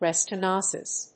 /ɹɛstɛˈnəʊsɪs(米国英語)/